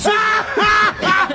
Play, download and share Risada do Bola Panico original sound button!!!!
risada-do-bola-panico.mp3